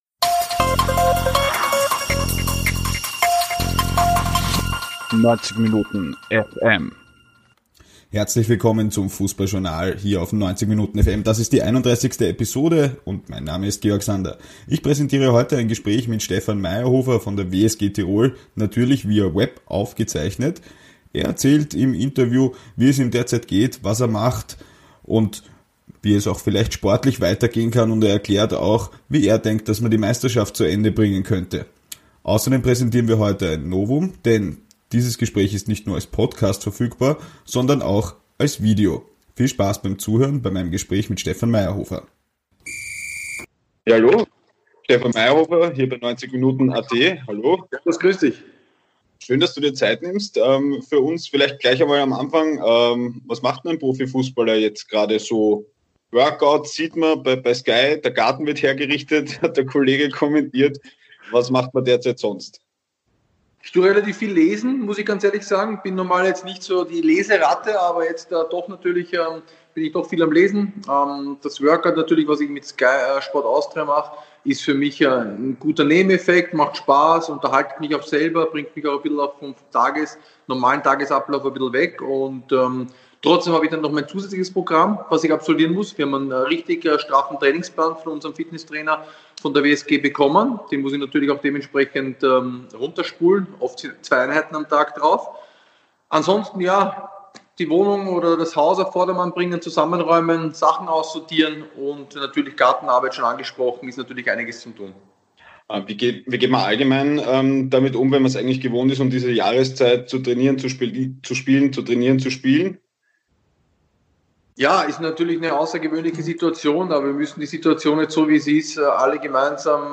Das Thema von Episode 31 vom 2. April 2020: 'Major' Stefan Maierhofer im Exklusivinterview Der 'Lange' kickt derzeit bei der WSG Tirol, kann auf eine lange und erfolgreiche Karriere im nationalen und internationalen Fußball zurückblicken.